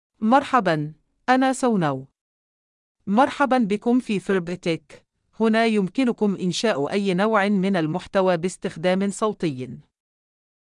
FemaleArabic (Jordan)
SanaFemale Arabic AI voice
Sana is a female AI voice for Arabic (Jordan).
Voice sample
Listen to Sana's female Arabic voice.
Sana delivers clear pronunciation with authentic Jordan Arabic intonation, making your content sound professionally produced.